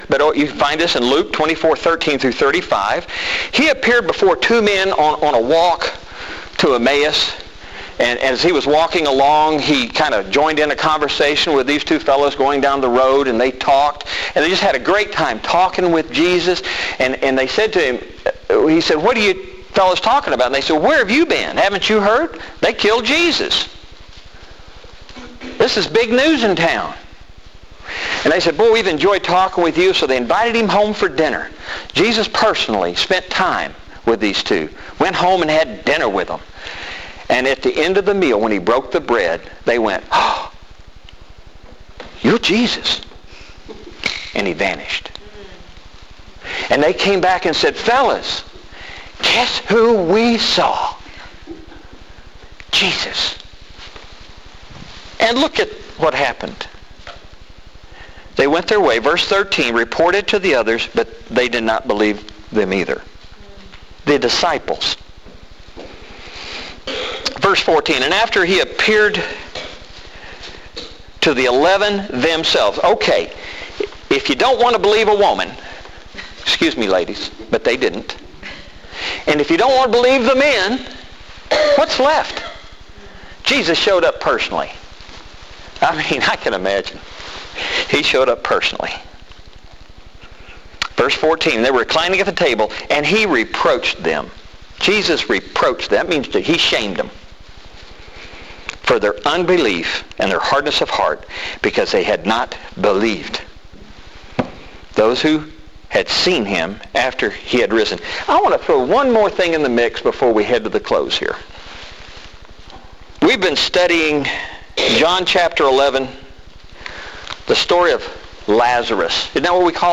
If this speaks to you, go to the “LONG” vervsion (a sermon on the matter)